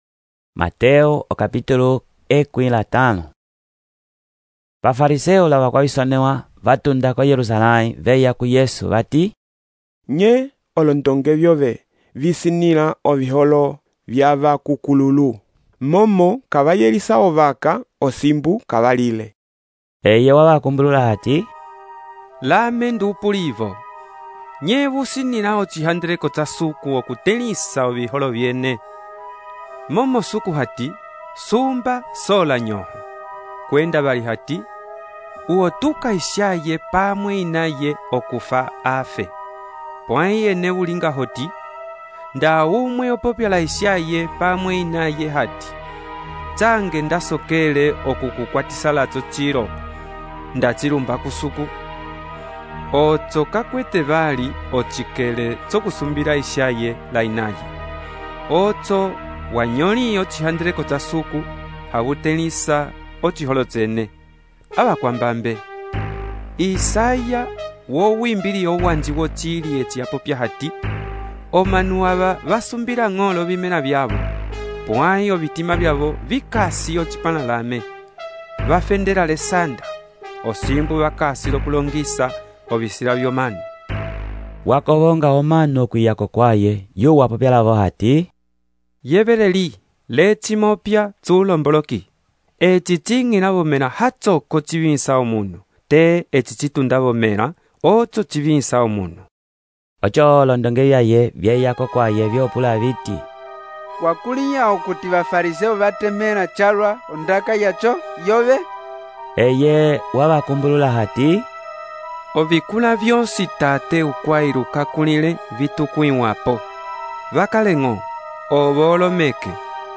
texto e narração , Mateus, capítulo 15